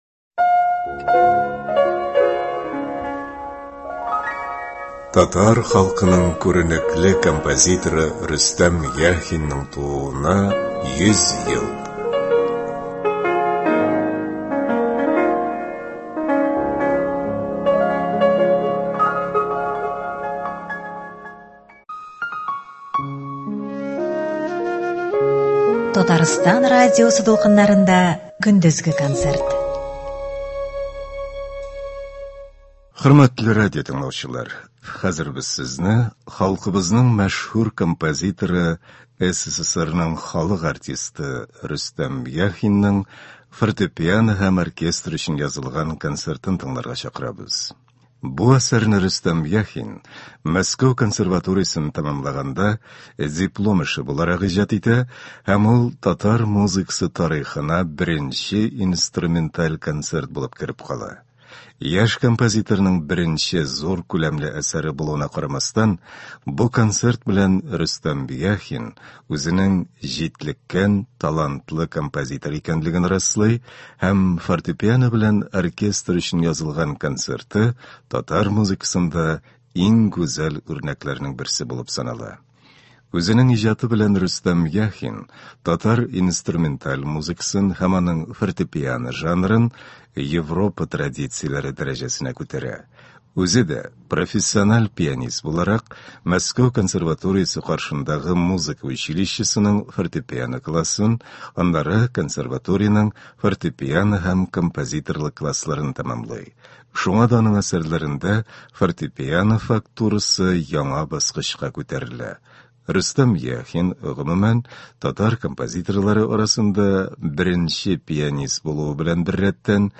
Фортепиано һәм оркестр өчен концерт.